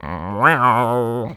Voice clip of Shy Guy taunting in Mario Power Tennis